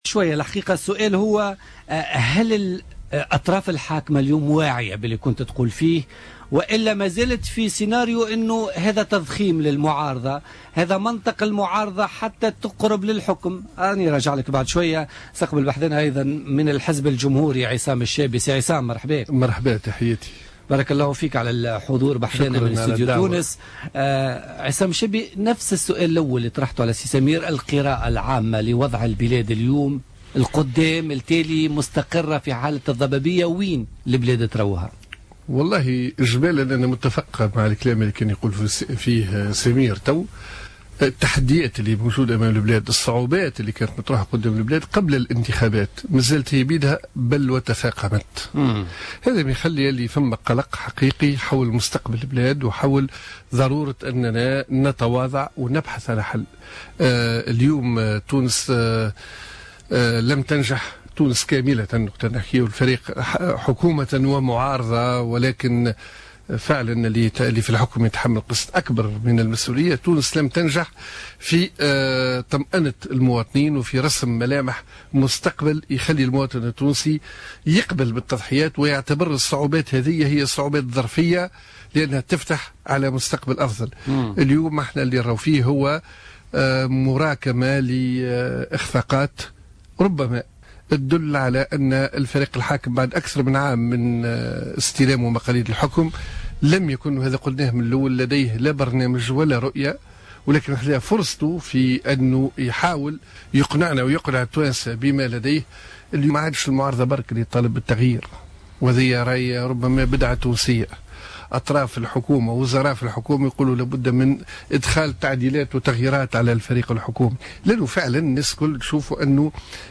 قال القيادي بالحزب الجمهوري عصام الشابي ضيف بوليتيكا اليوم الأربعاء 20 أفريل 2016 إن الصعوبات التي كانت مطروحة قبل الإنتخابات مازالت هي ذاتها بل تفاقمت اليوم .